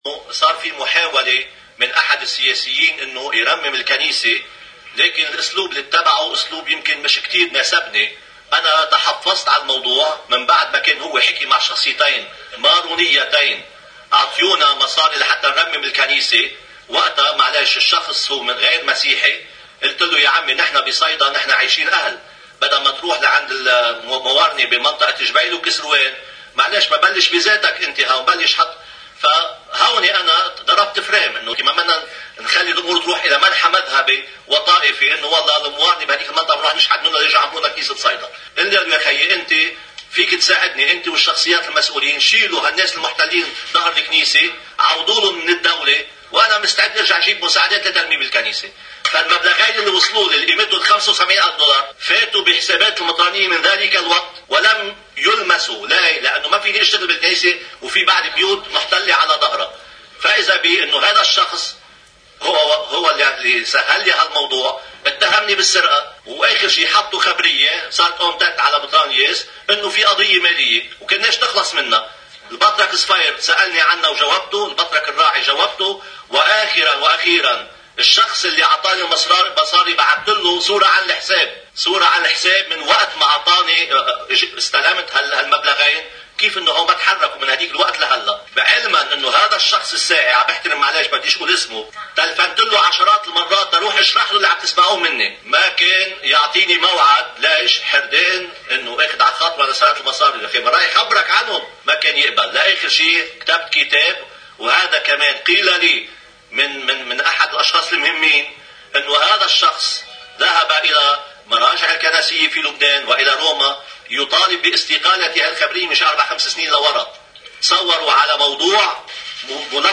مقتطف من المؤتمر الصحفي للمطران نصار، بخصوص ترميم كنيسة صيدا، وقصة الأموال: (الجزء 2)